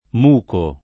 vai all'elenco alfabetico delle voci ingrandisci il carattere 100% rimpicciolisci il carattere stampa invia tramite posta elettronica codividi su Facebook muco [ m 2 ko ] (antiq. mucco ) s. m. («liquido vischioso»); pl. -chi